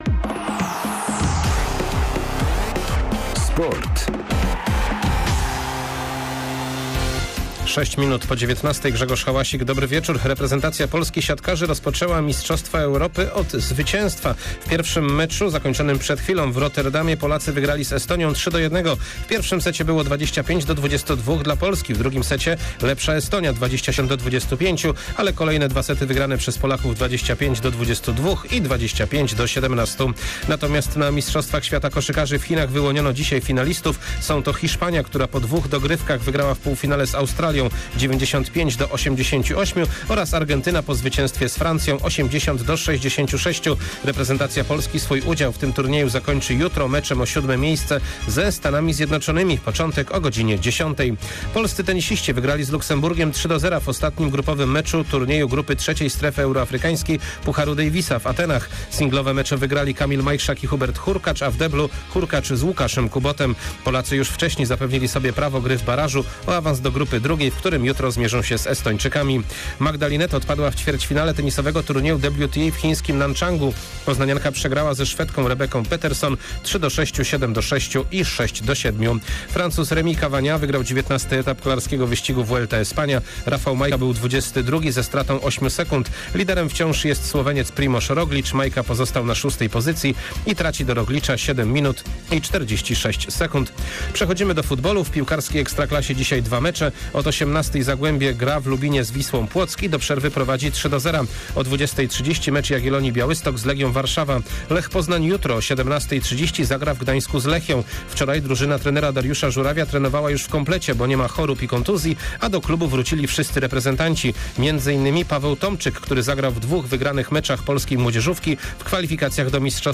13.09. SERWIS SPORTOWY GODZ. 19:05